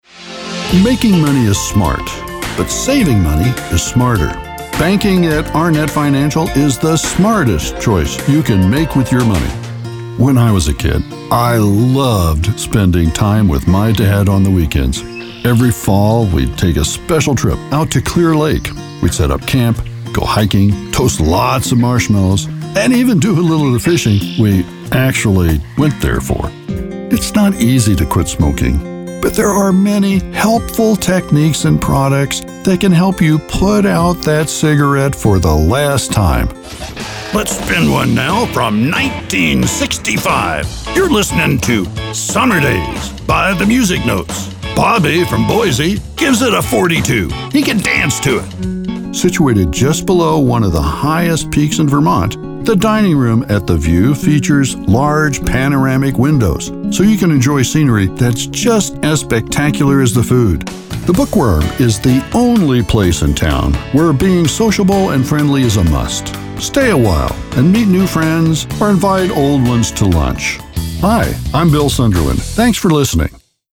My age range is 50 to 75 years, US-English.
Commercial Demo Click Here!